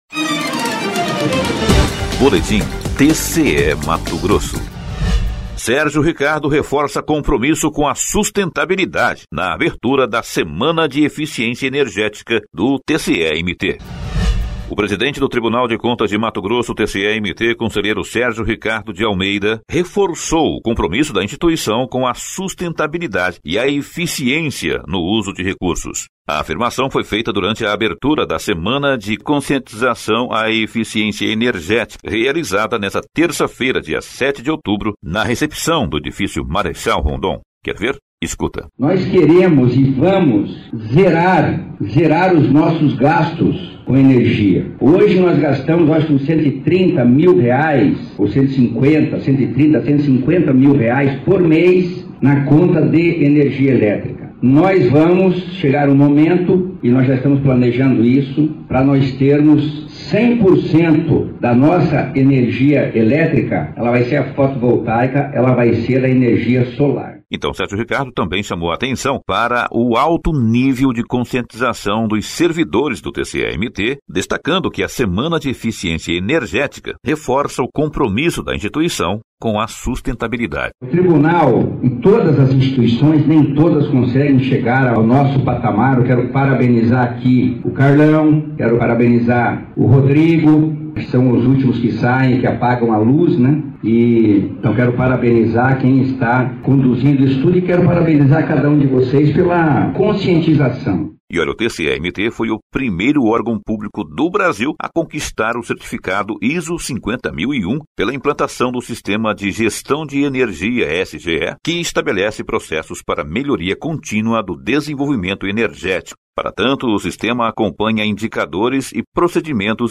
Sonora: Sérgio Ricardo – conselheiro-presidente do TCE-MT